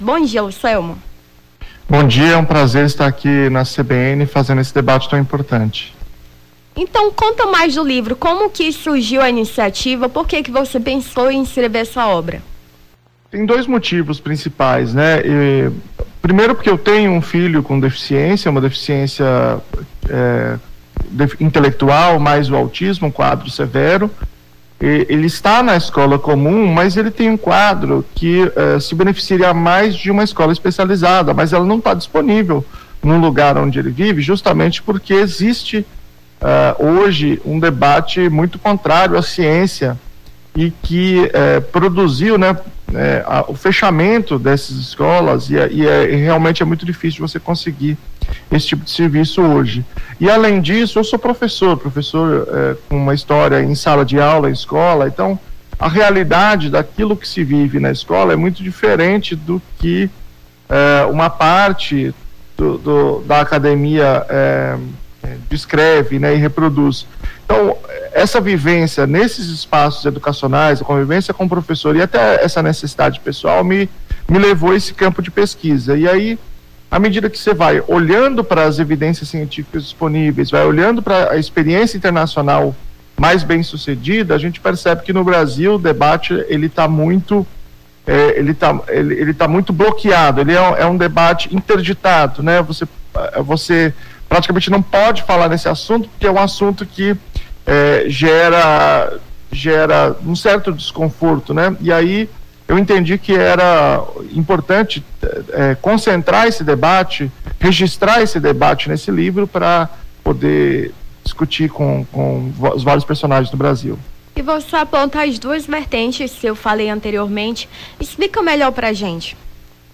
Nome do Artista - CENSURA - ENTREVISTA (LIVRO CRITICA PSEUDOCIENCIA) 17-05-23.mp3